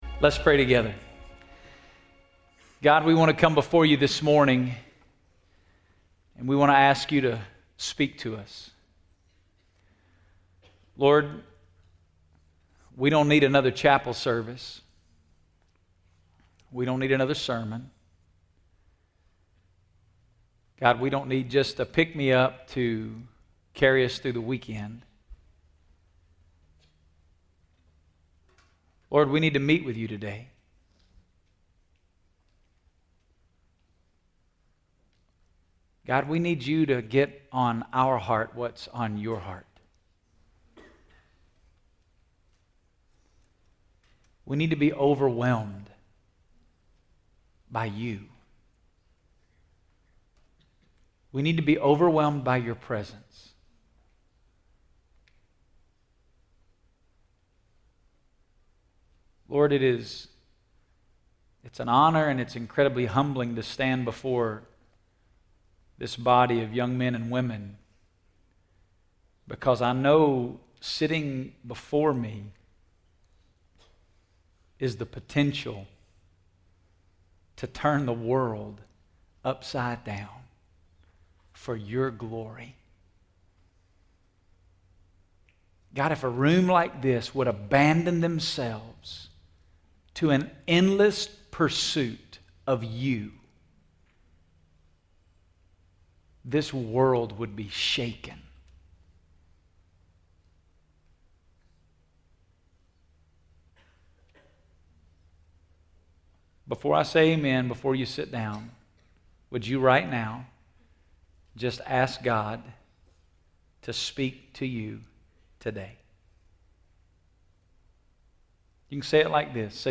Message from Acts 1:1-14